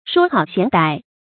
說好嫌歹 注音： ㄕㄨㄛ ㄏㄠˇ ㄒㄧㄢˊ ㄉㄞˇ 讀音讀法： 意思解釋： 說這個好，嫌那個壞。